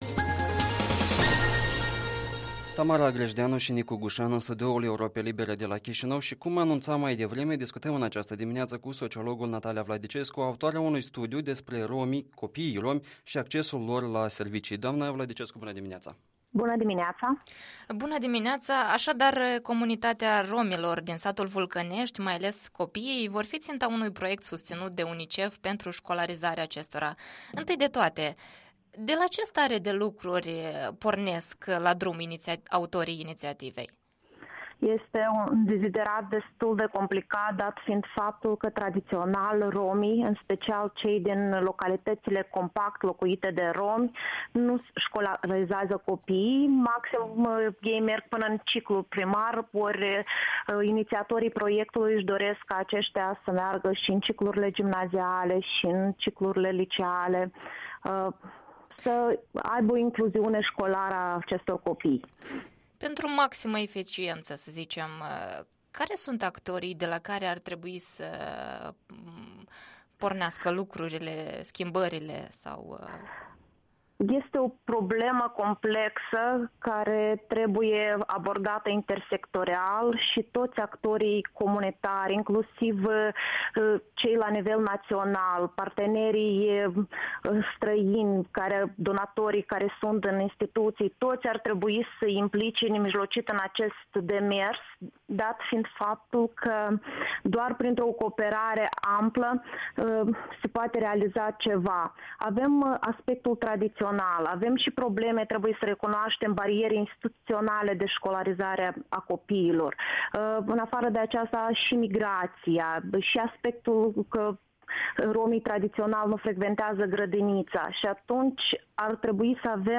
Interviul matinal la EL despre un proiect de integrare a copiilor de etnie romă în sistemul educațional.